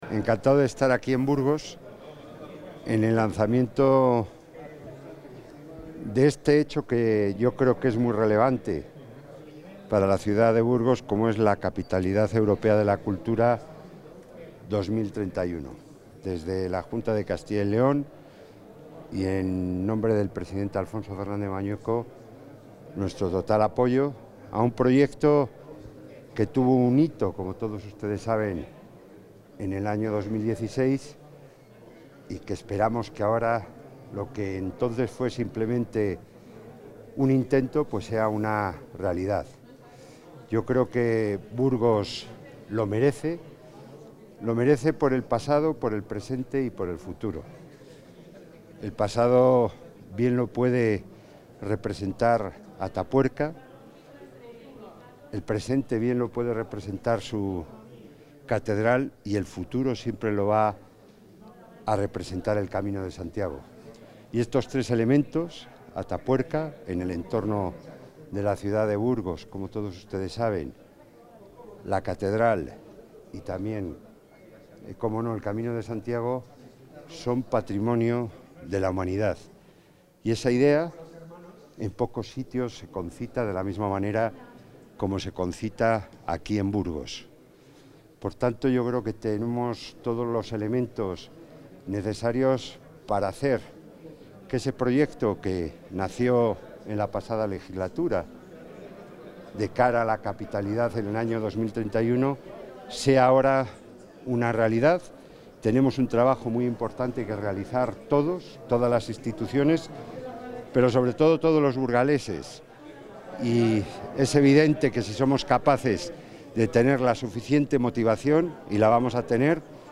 Intervención del consejero de la Presidencia.
El consejero de la Presidencia, Jesús Julio Carnero, ha apoyado hoy a la ciudad de Burgos en su acto de lanzamiento como candidata a Capital Europea de la Cultura 2031.